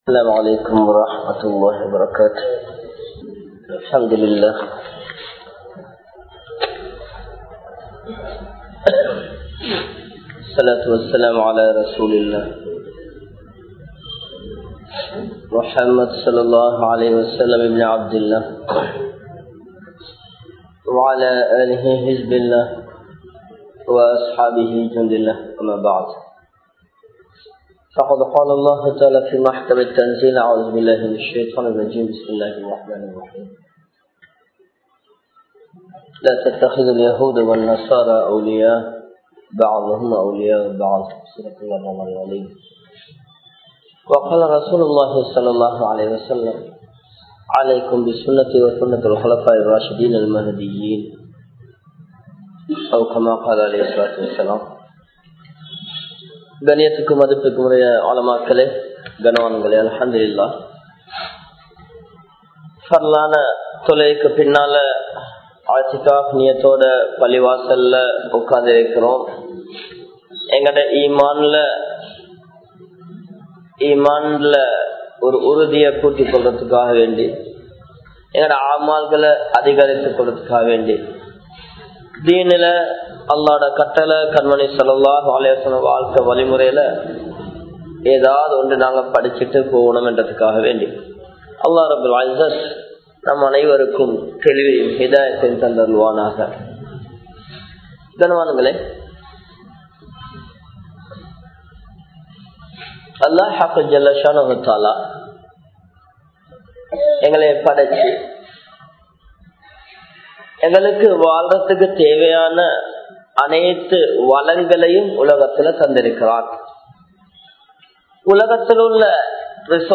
Youth & Technology | Audio Bayans | All Ceylon Muslim Youth Community | Addalaichenai